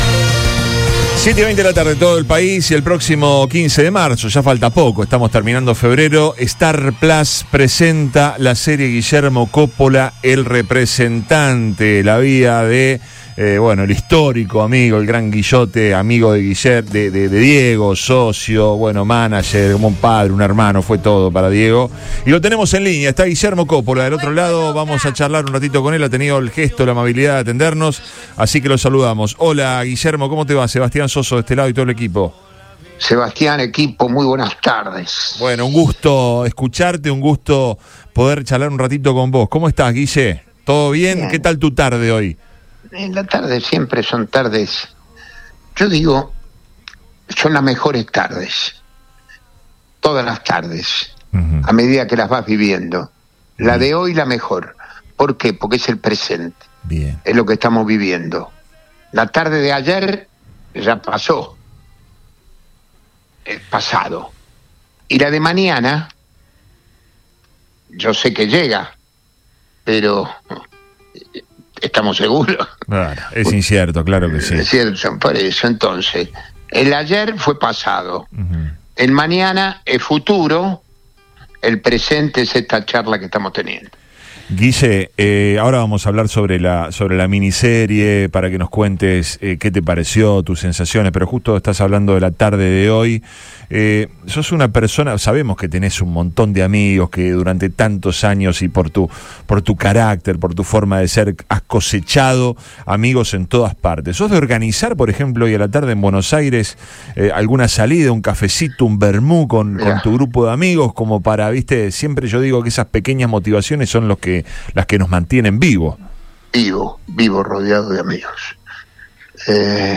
El ex representante de Diego Armando Maradona, Guillermo Coppola dialogó con el equipo de Después de Todo en Radio Boing. Compartió su mirada sobre las expectativas que tiene de cara al estreno de la serie “El Representante”, que repasa su trayectoria y que tendrá a Juan Minujín como el actor que lo representa. Además, recordó anécdotas sobre sus años vividos con Maradona.